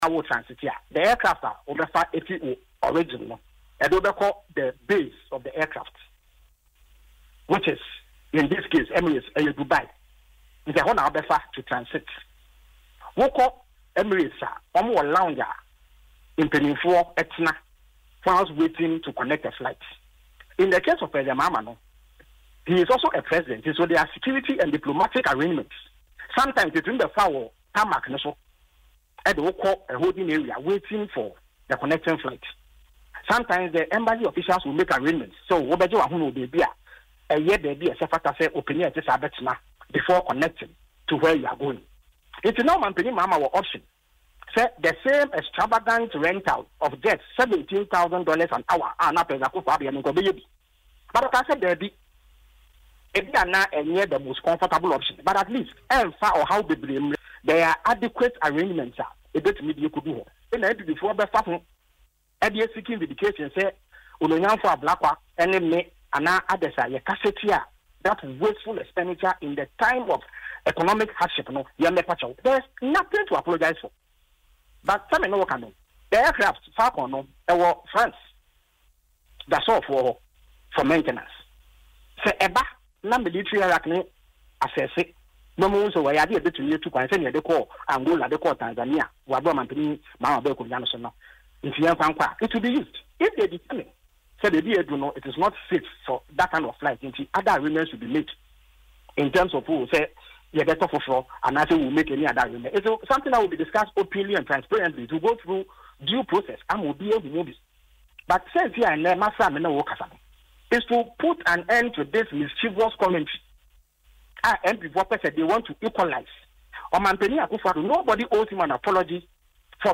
Speaking on Asempa FM’s Ekosii Sen, the MP for Abura Asebu Kwamankese described the claims as false and politically motivated.